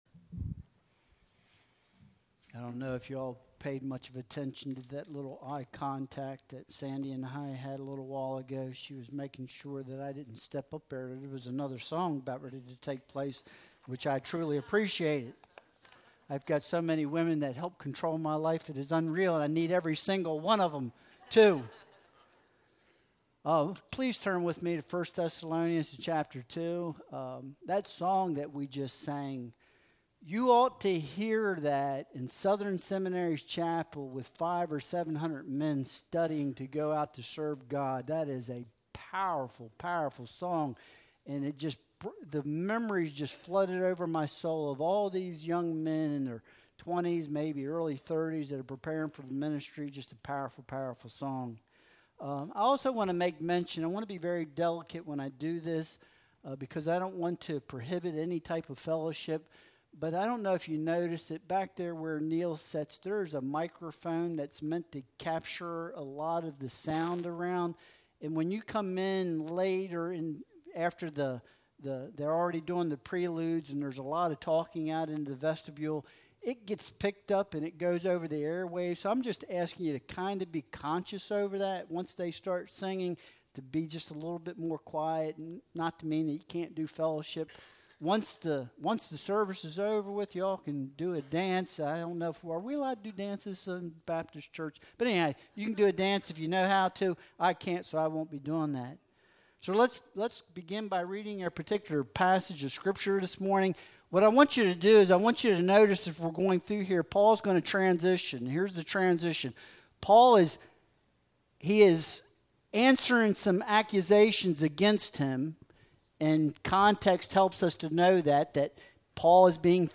1 Thessalonians 2.1-2 Service Type: Sunday Worship Service Download Files Bulletin « You Make Me Smile Anticipating the Parousia